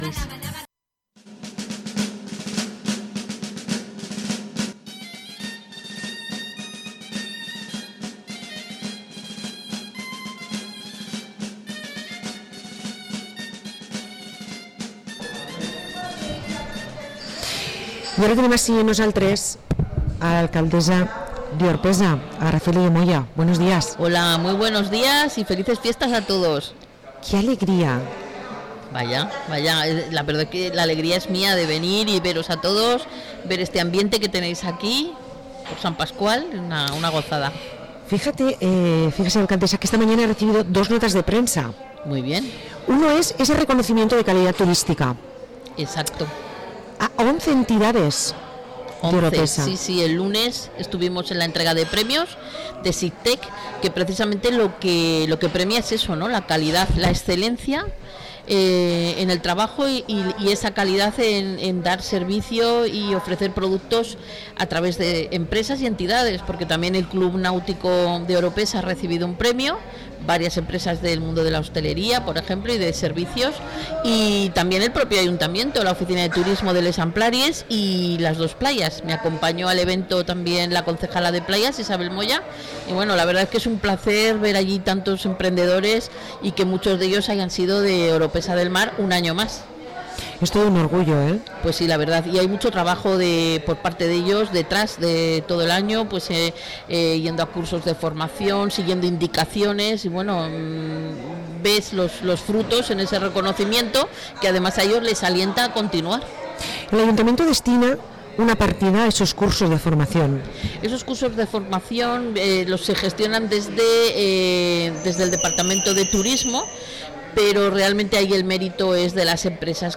Parlem amb Aracelia de Moya, alcaldessa d´Orpesa